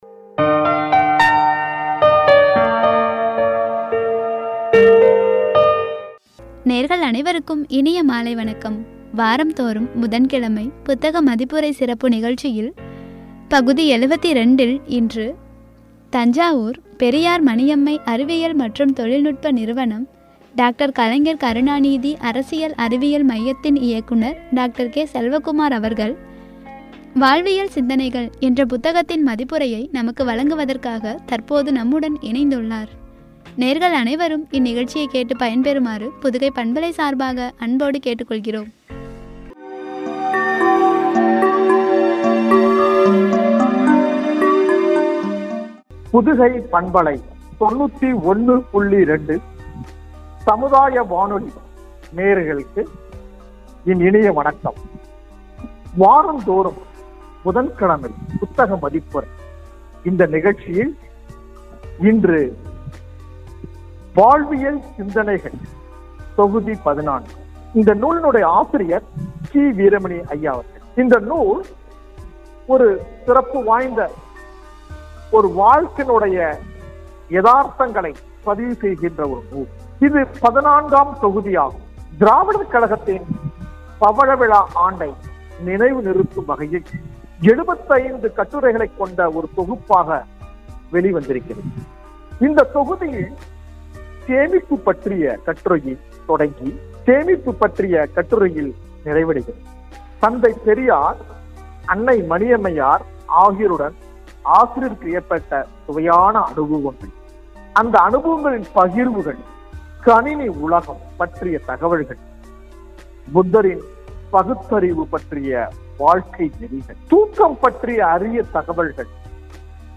“வாழ்வியல் சிந்தனைகள்” புத்தக மதிப்புரை(பகுதி – 72), குறித்து வழங்கிய உரை.